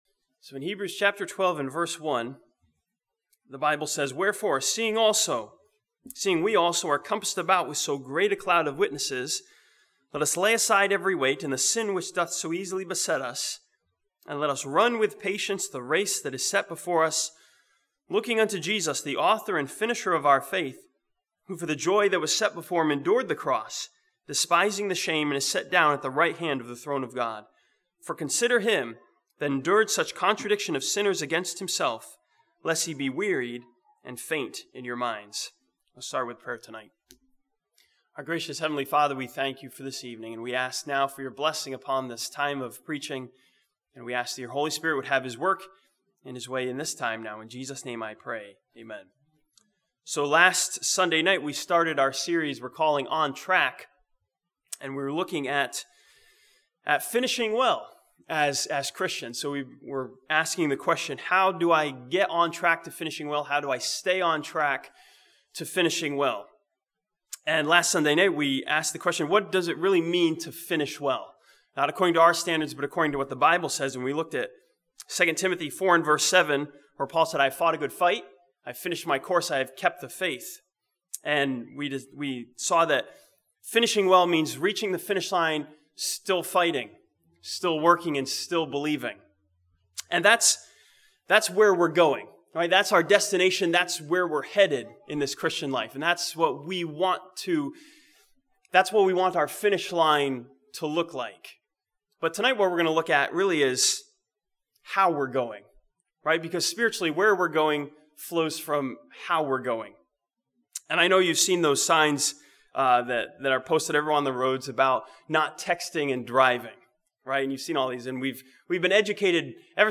This sermon from Hebrews chapter 12 continues the series "On Track" by studying the exhortation to consider Him.